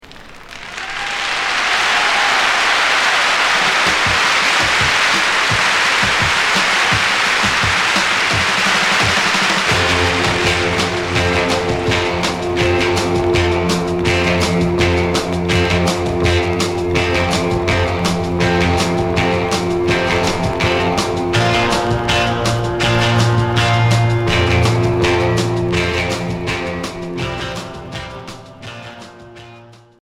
Rock surf Quatrième EP retour à l'accueil